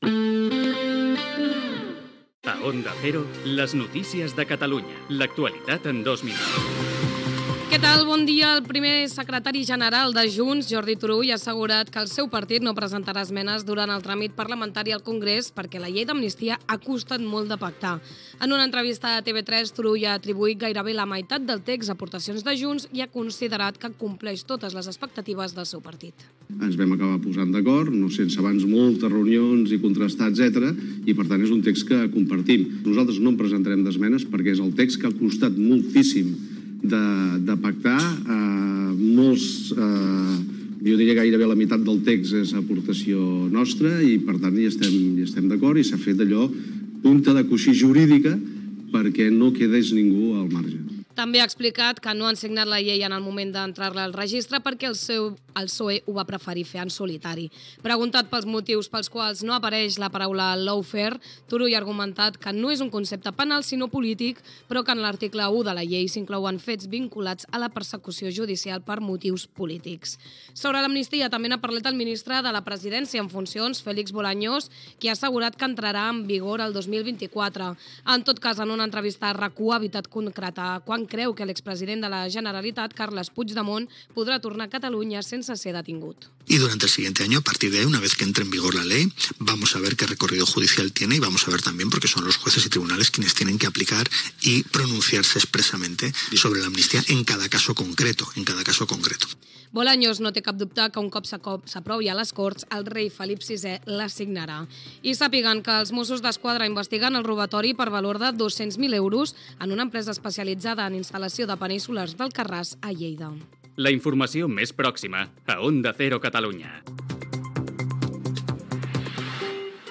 Publicitat, promoció de "Julia en la onda" i indicatiu.
Informatiu
FM